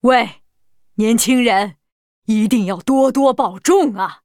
文件 文件历史 文件用途 全域文件用途 Balena_amb_02.ogg （Ogg Vorbis声音文件，长度4.3秒，99 kbps，文件大小：51 KB） 源地址:游戏语音 文件历史 点击某个日期/时间查看对应时刻的文件。